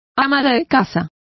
Complete with pronunciation of the translation of housekeeper.